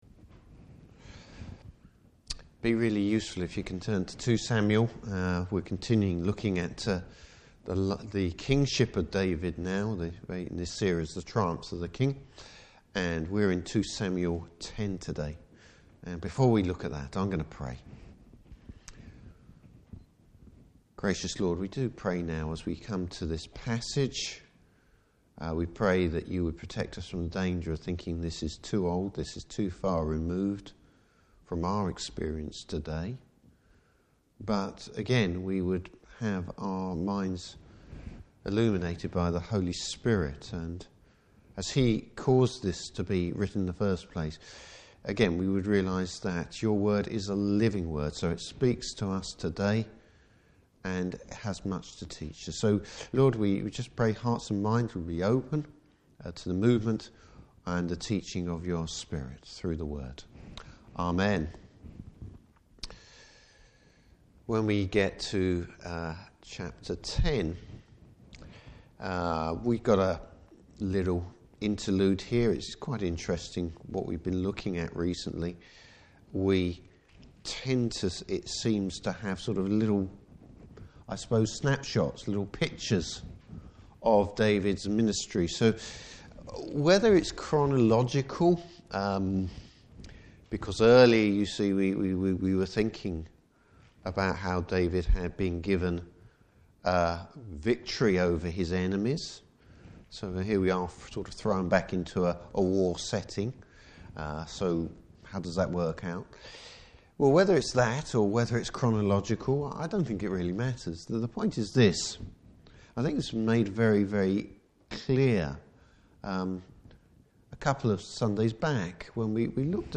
Service Type: Evening Service David’s restraint when provoked.